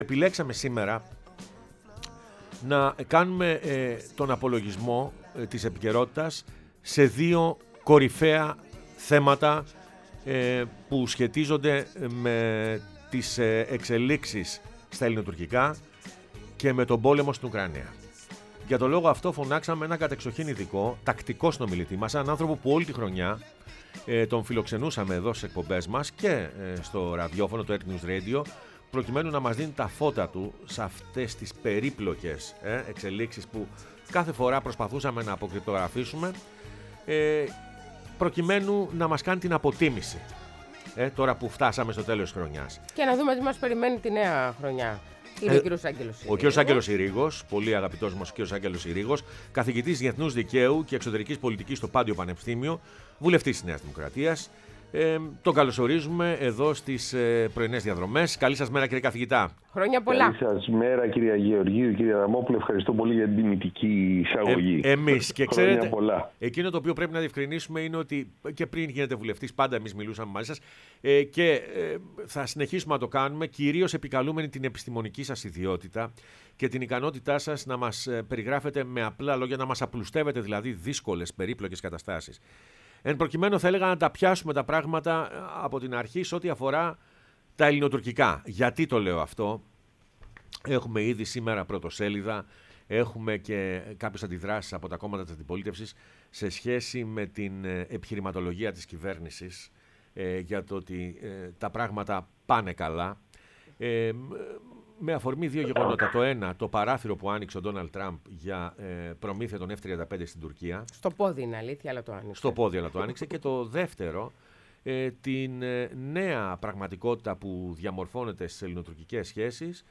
Ο Άγγελος Συρίγος , καθηγητής Διεθνούς Δικαίου και Εξωτερικης Πολιτικής Πάντειο Πανεπιστήμιο και βουλευτής Νέας Δημοκρατίας,μίλησε στην εκπομπή Πρωινές Διαδρομές